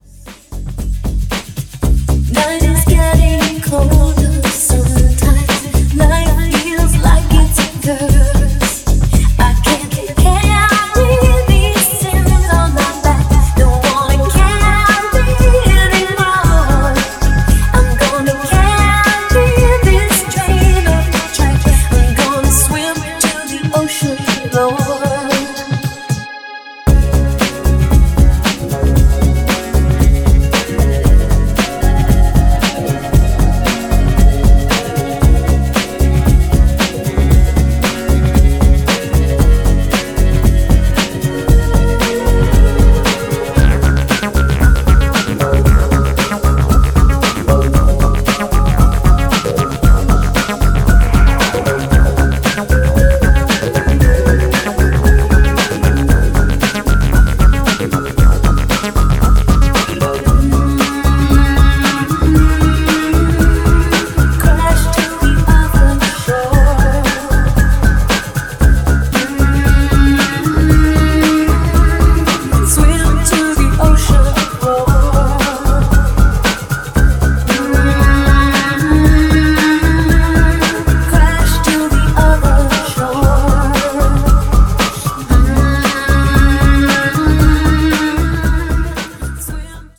BPM115のアシッド・ブレイクビーツを展開するA-2も、非常にエクスタティックな仕上がりでナイス。